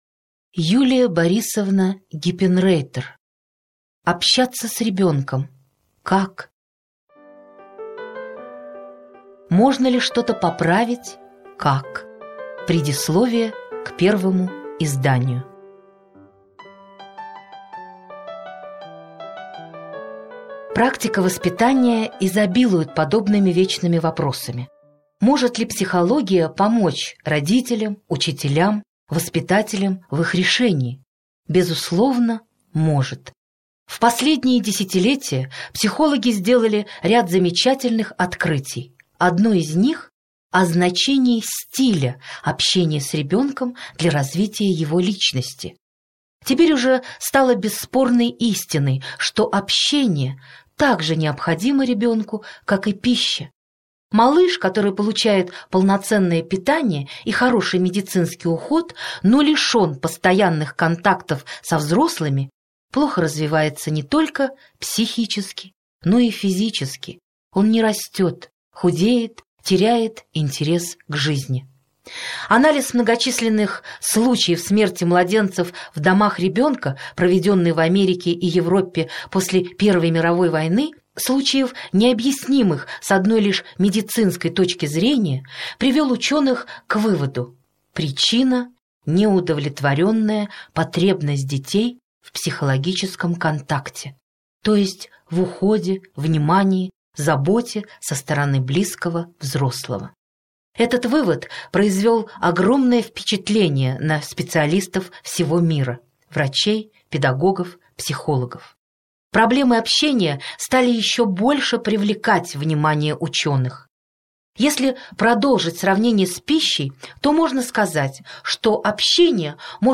Аудиокнига Общаться с ребенком. Как?